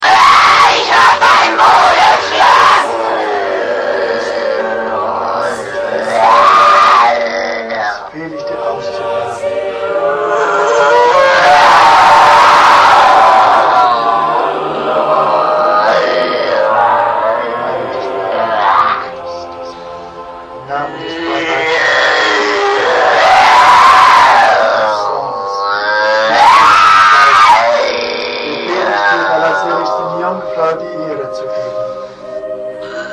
kriki-besa-vnutri-chelovecheskogo-tela-i-zvuki-ekzorcizma
• Категория: Звуки из ада
• Качество: Высокое